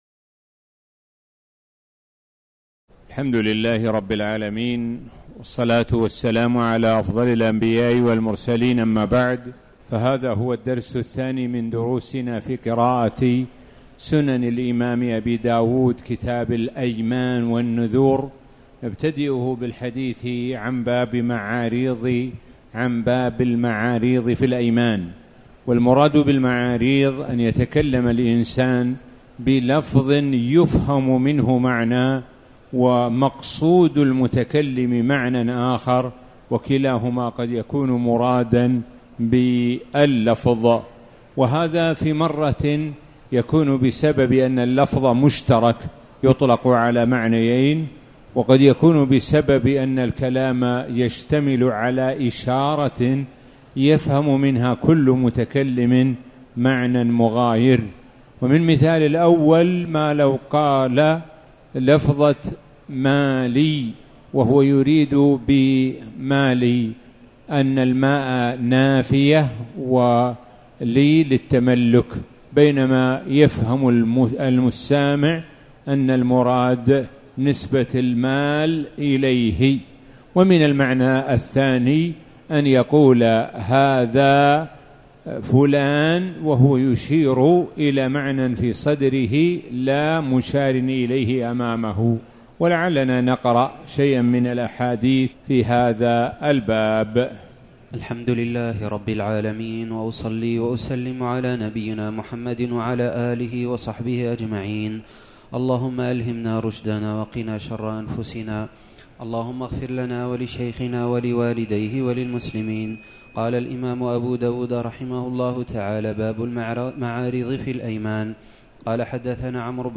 تاريخ النشر ٢٤ ذو القعدة ١٤٣٩ هـ المكان: المسجد الحرام الشيخ: معالي الشيخ د. سعد بن ناصر الشثري معالي الشيخ د. سعد بن ناصر الشثري باب المعاريض في الإيمان